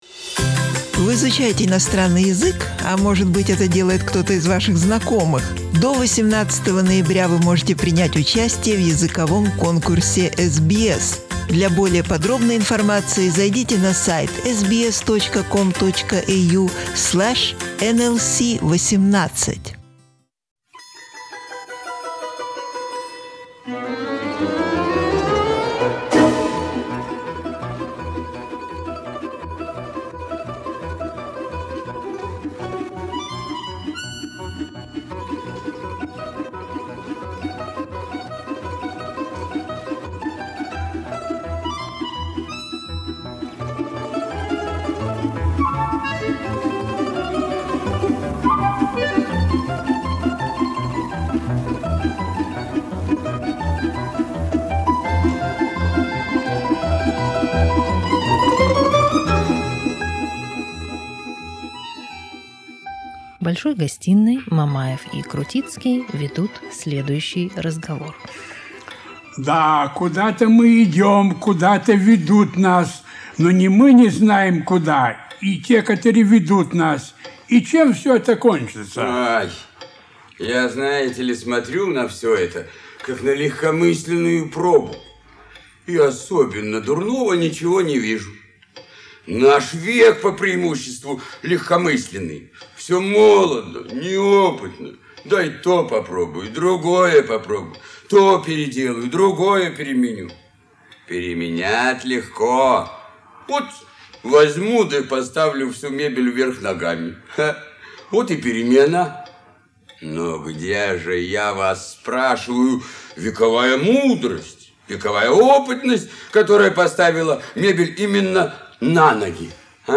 Melbourne Russian Theatrical Studio 1995 Source: SBS
His energy attracted to the studio many amateur and professional actors of different ages from many different cities of the former USSR.